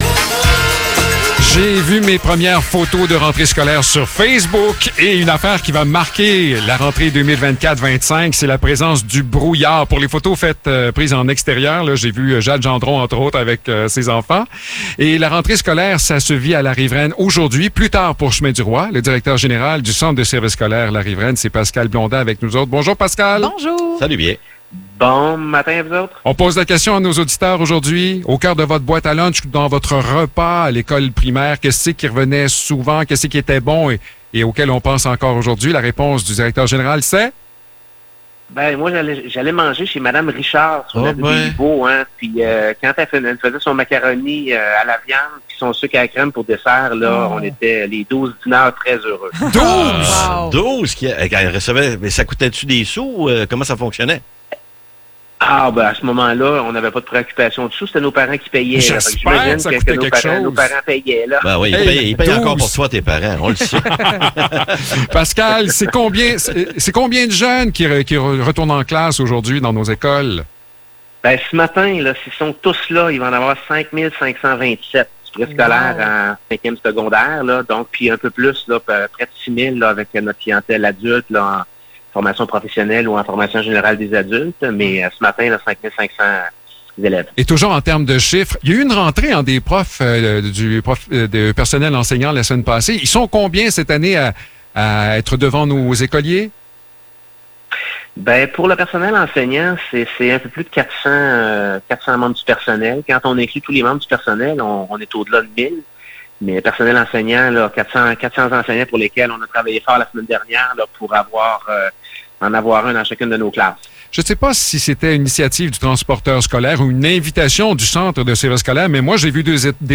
Entrevue pour la rentrée à la Riveraine